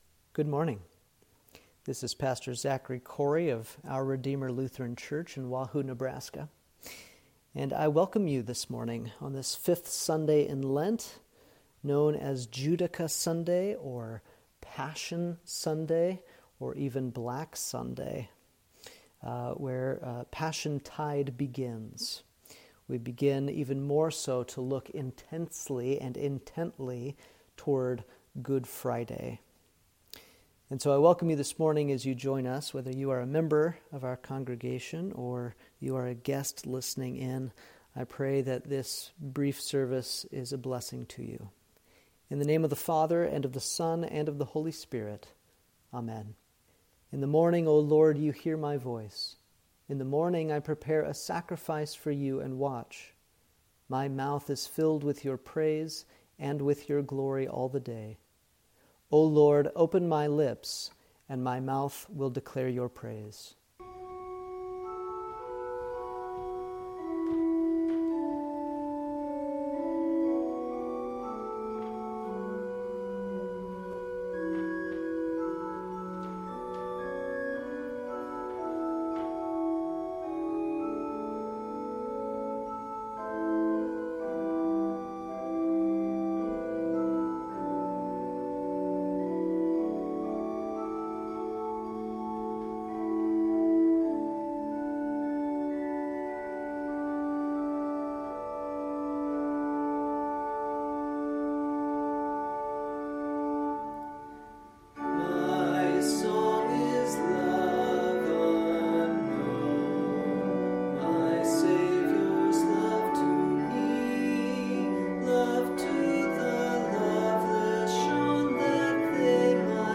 Service: Fifth Sunday in Lent – Judica